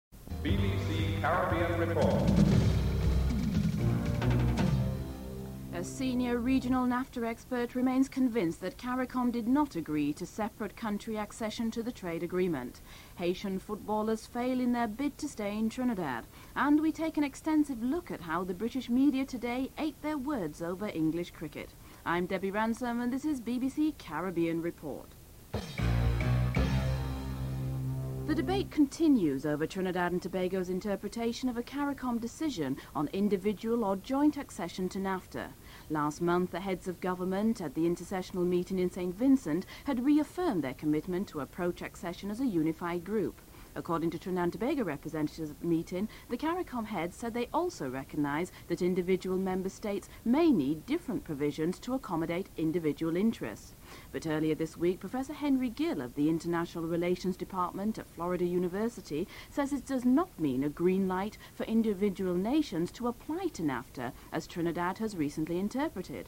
The recording is not clear from 14 min 14 sec.
5. Wrap up & Theme music (13:42-14:44)